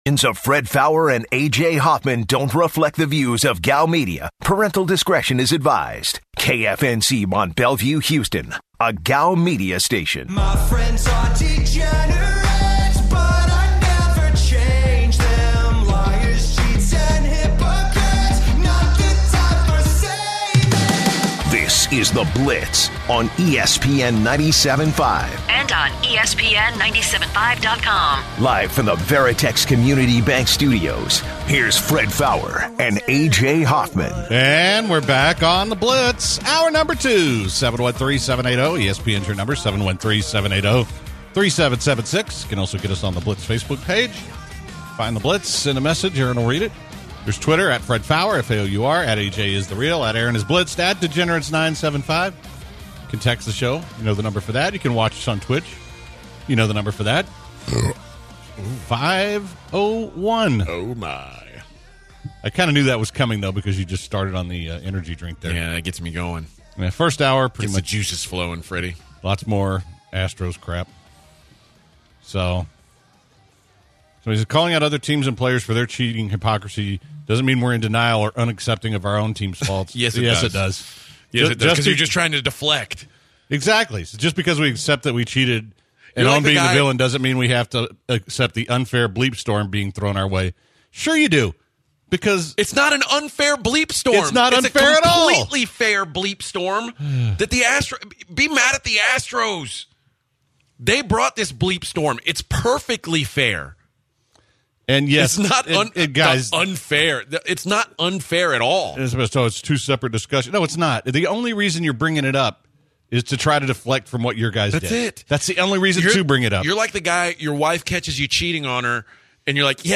The guys also talk more about the Astros cheating scandal and get the opinions of several different callers.